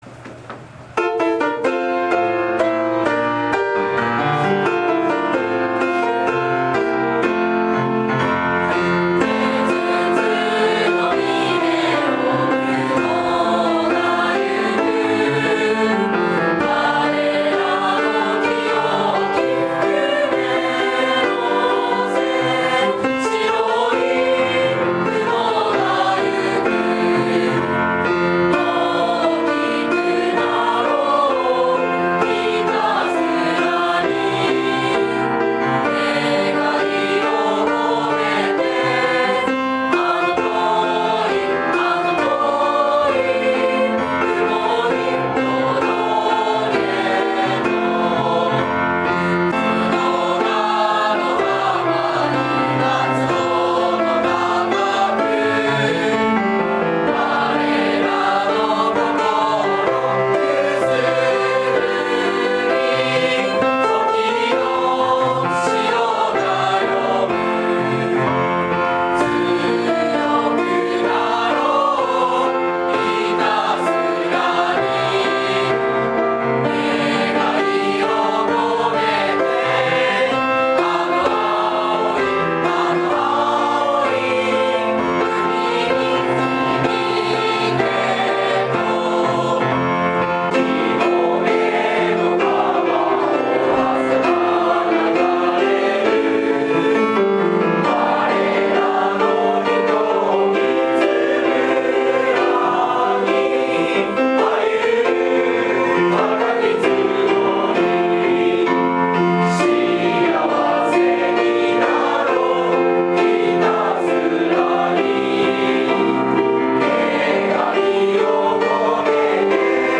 平成２８年度卒業生が歌っています。
（１番は３年１組、２番は２組、３番は３組です。）
作詞　家高　正彦　　　作曲　長谷川　汪示